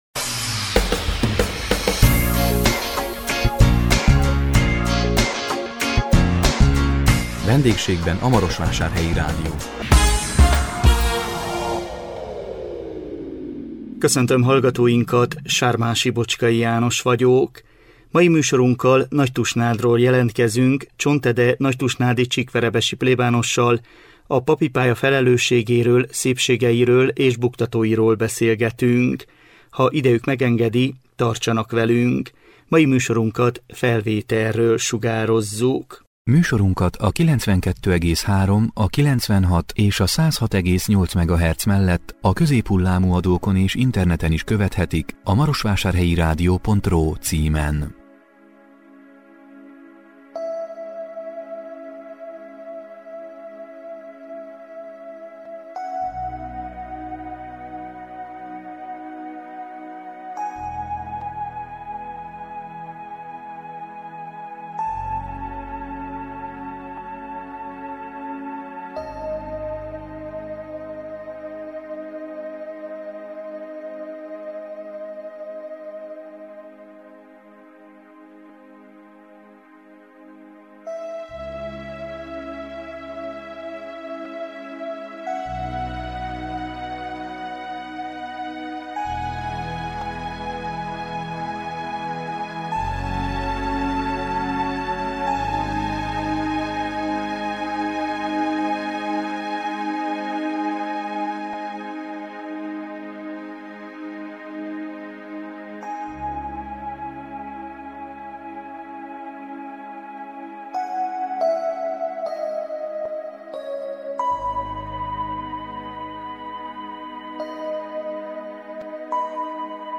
Nagytusnádon vendégeskedtünk